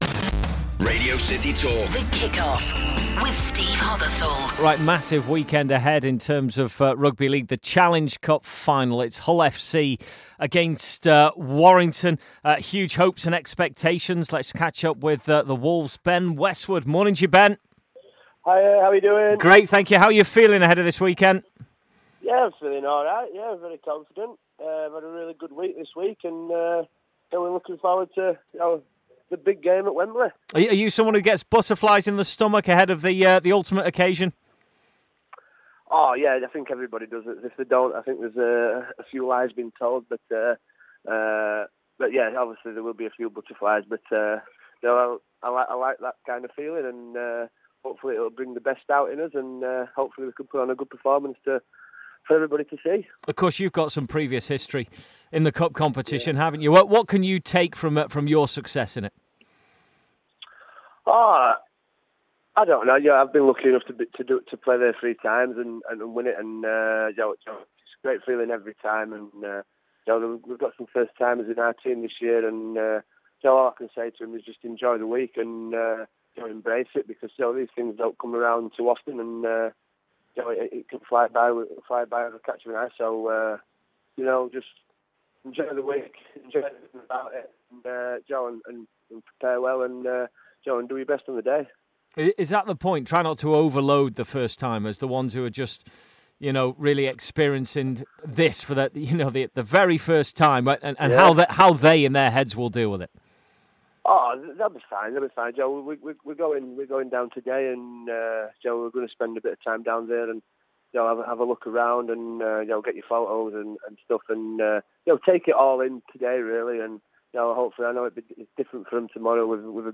Warrington Wolves star Ben Westwood speaks to us ahead of the Challenge Cup final against Hull